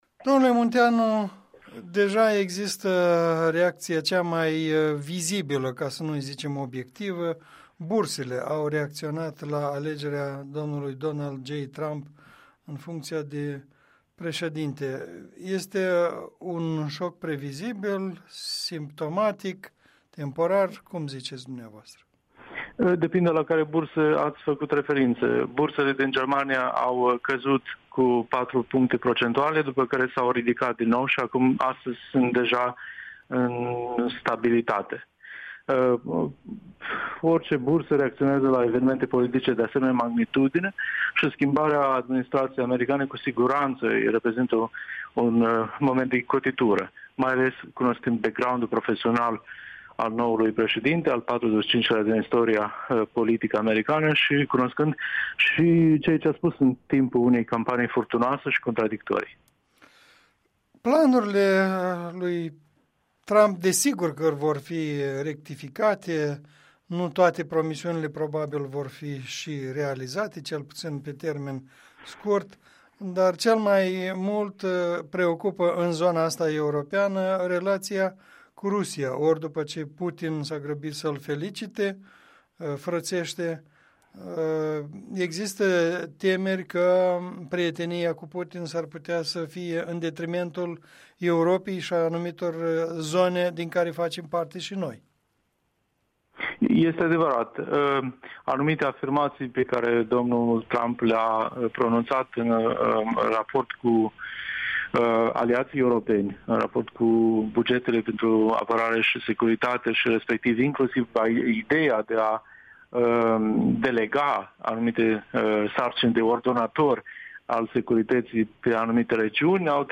Rezultatele alegerilor prezidențiale americane - o discuție cu un fost diplomat moldovean